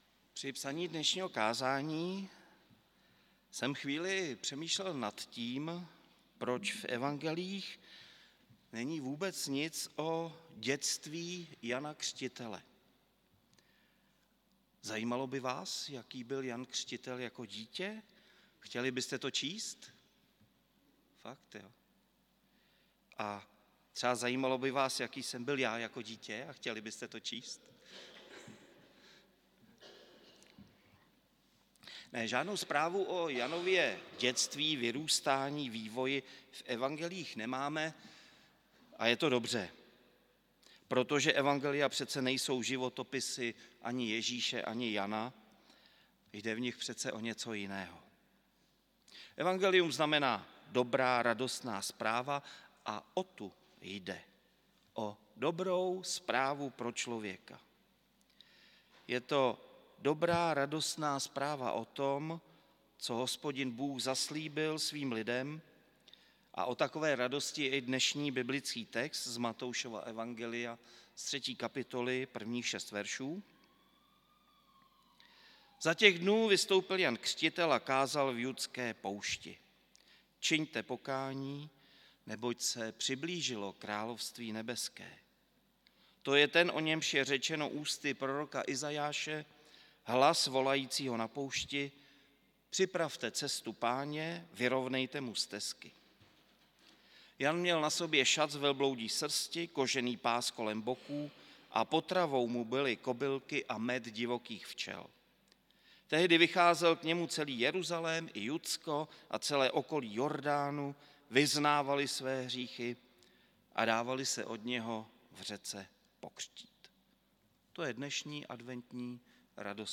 audio kázání